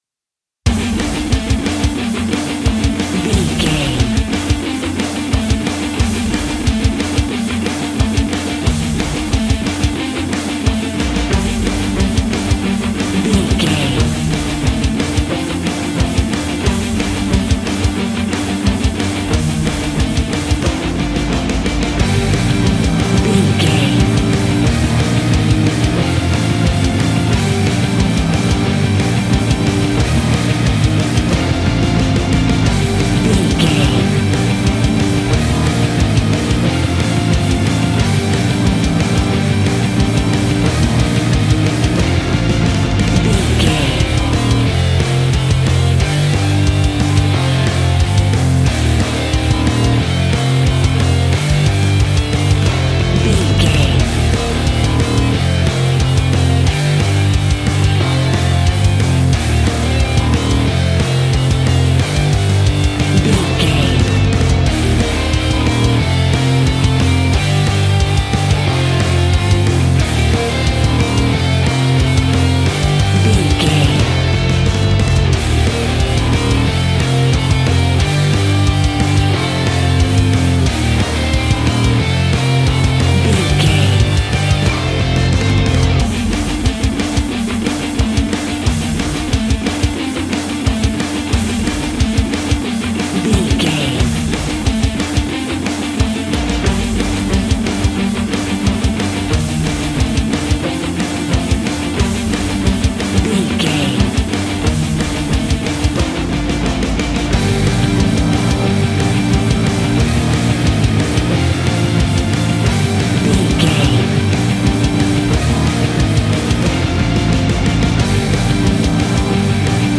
Epic / Action
Fast paced
Aeolian/Minor
hard rock
heavy metal
instrumentals
Heavy Metal Guitars
Metal Drums
Heavy Bass Guitars